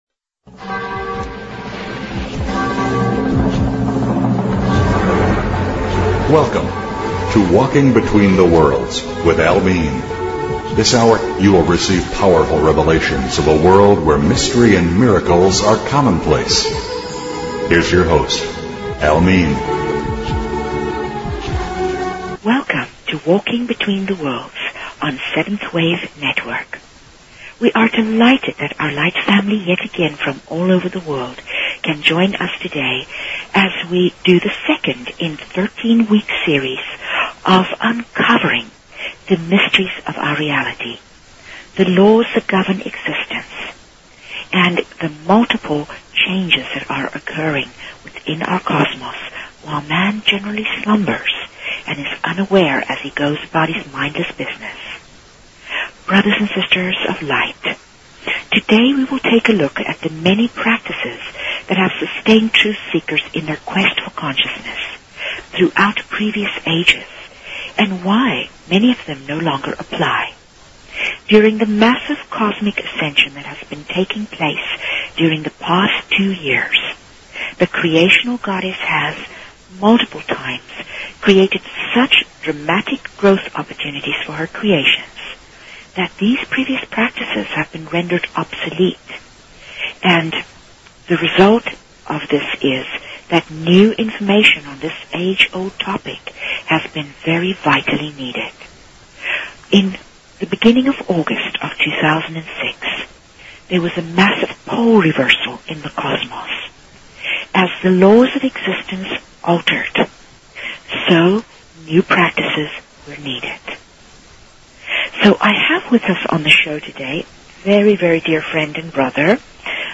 Talk Show Episode, Audio Podcast, Opening_the_Doors_of_Heaven and Courtesy of BBS Radio on , show guests , about , categorized as